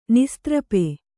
♪ nistrape